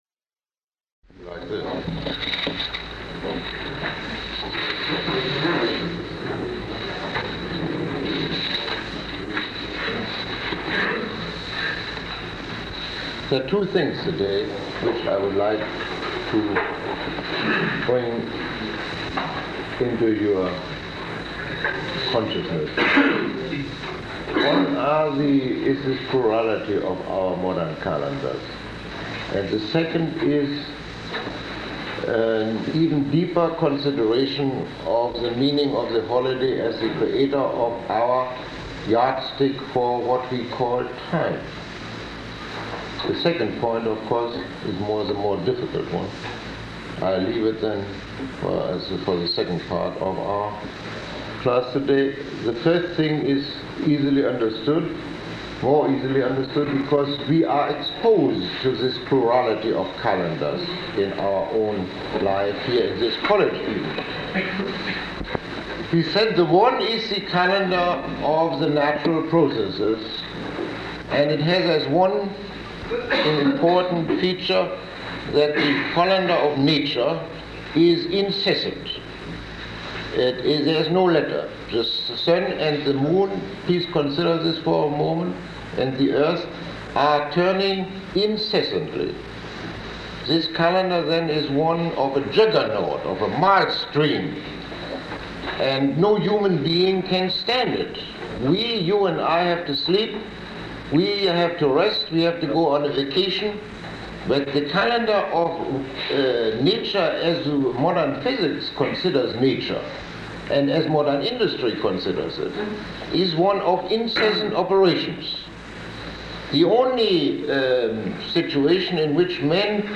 Lecture 06